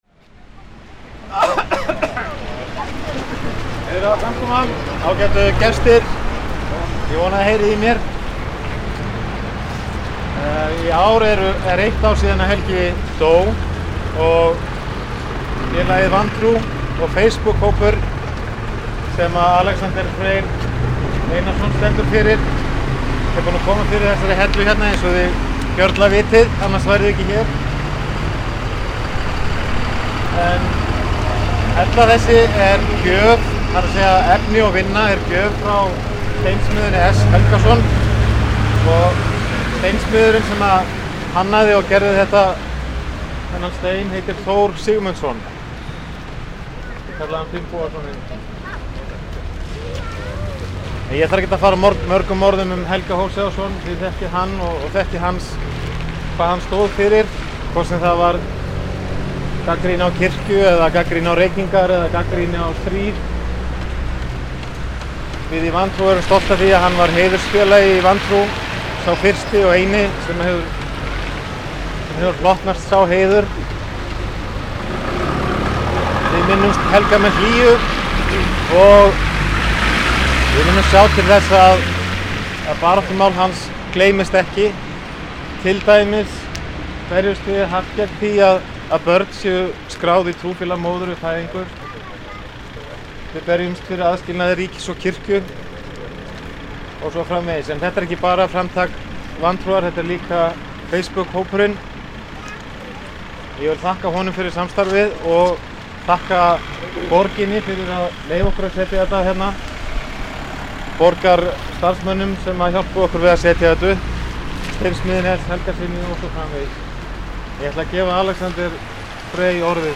Þann 6. september 2010, ári frá andláti Helga, stóðu samtökin Vantrú og Facebook hópur að því að afhjúpa gangstéttarhellu. Var hún lögð á eitt þeirra götuhorna sem Helgi var vanur að standa við, á hornið á mótum Langholtsvegar og Holtavegar . Er þetta stutt hljóðritun frá þeirri athöfn.